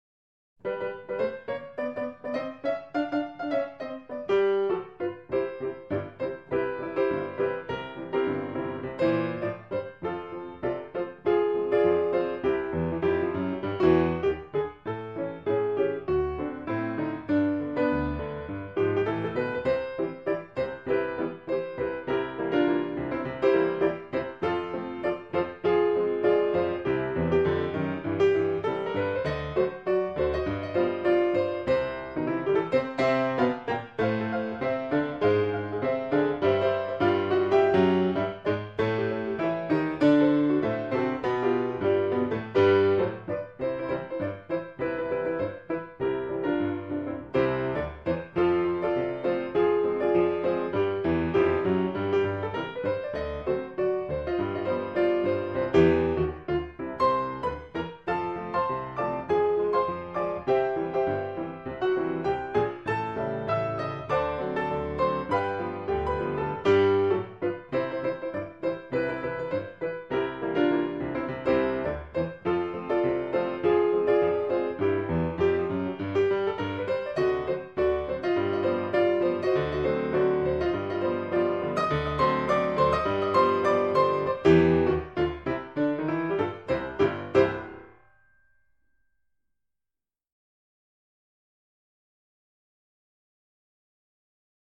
เปียโน